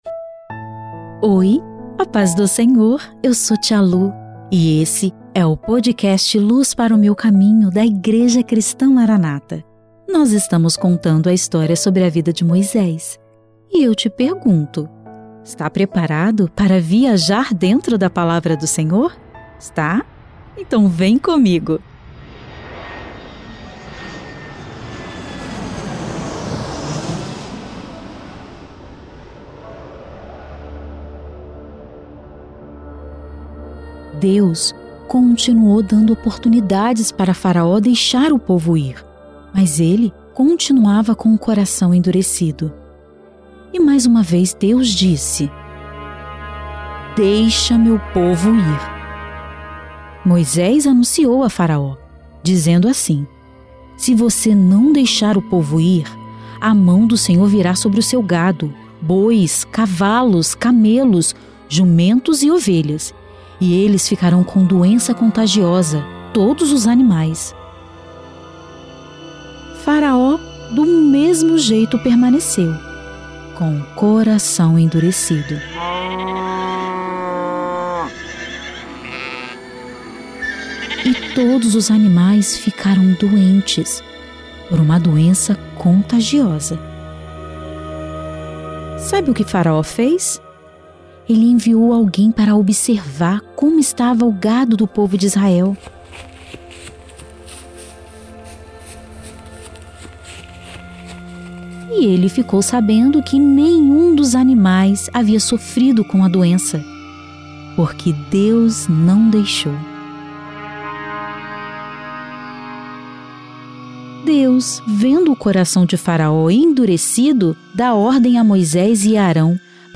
O quadro Luz Para o Meu Caminho traz histórias da bíblia narradas em uma linguagem para o público infantil.